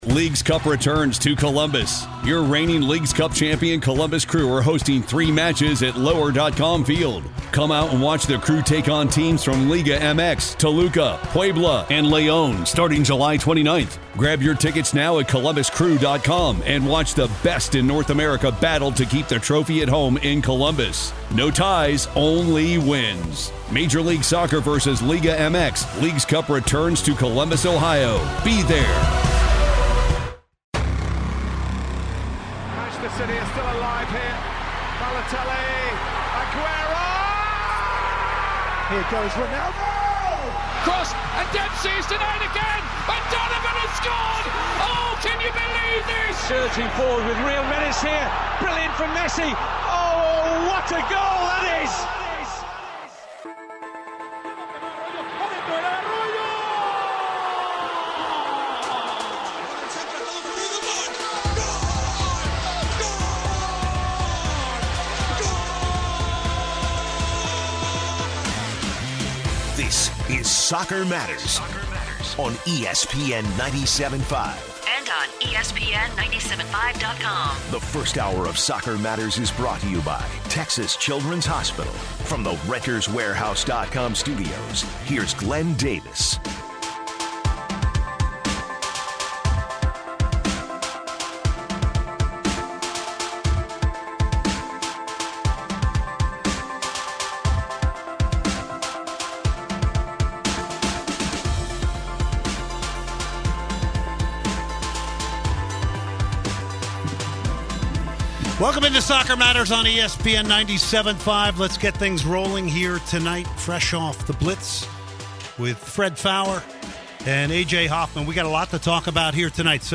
Becky Sauerbrunn, US Women’s National Team and Utah Royals defender joins the show. They talk about the women’s chances in the upcoming FIFA Women’s World Cup and her career thus far.